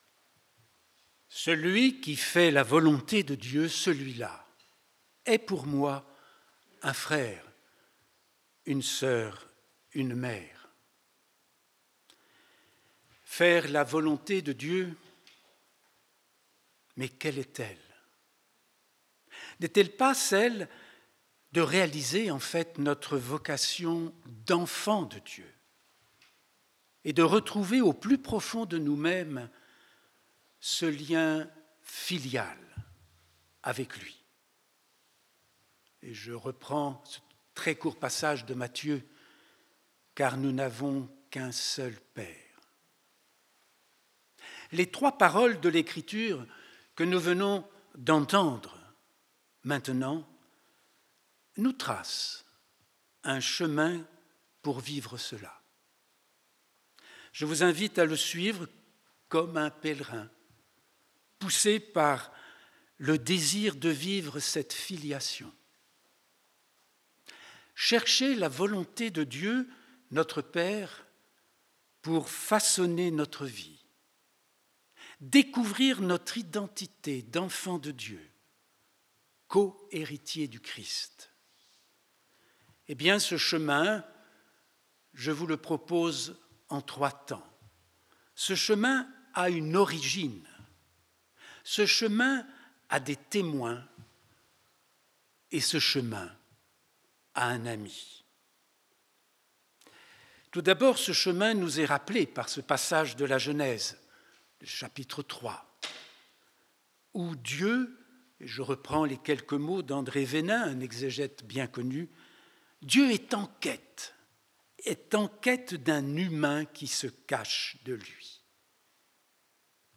L'homélie